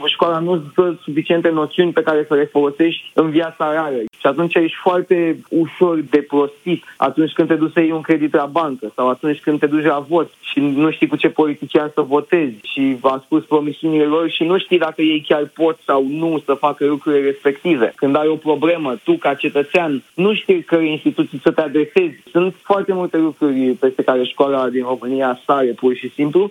Selly a vorbit în emisiunea Deșteptarea despre cât de importante sunt aceste schimbări.